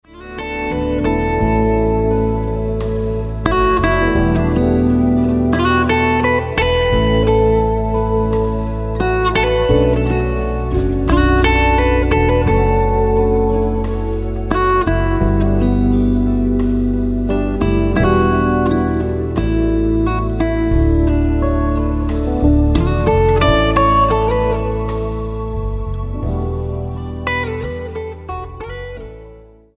STYLE: MOR / Soft Pop
A relaxing and soothing selection of 14 instrumental tracks
acoustic and electric guitars